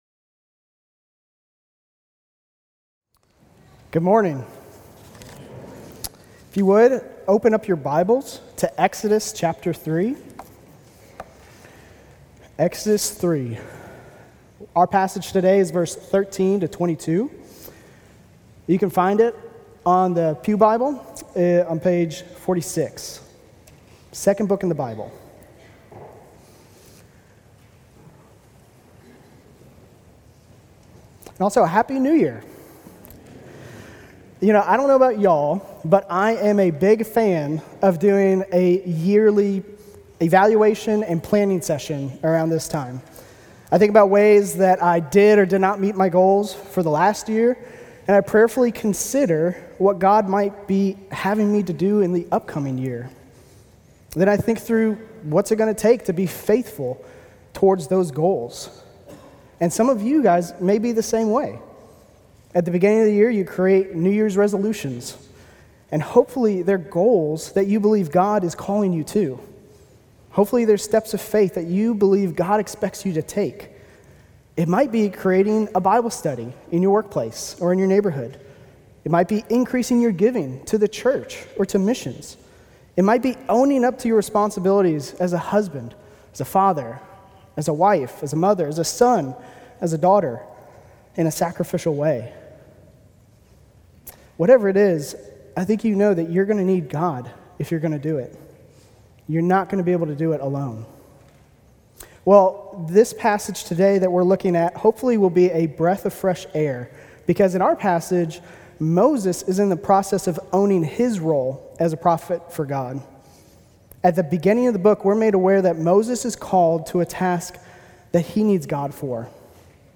The Almighty "I Am" - Mount Vernon Baptist Church : Mount Vernon Baptist Church